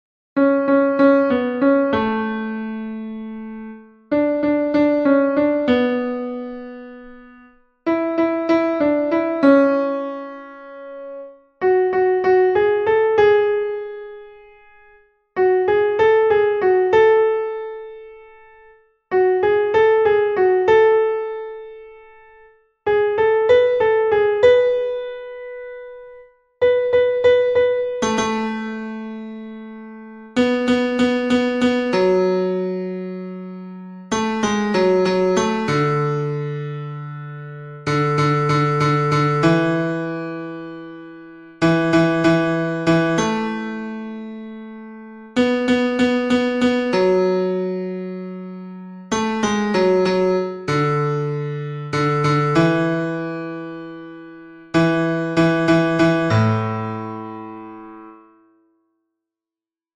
basses-mp3 31 janvier 2021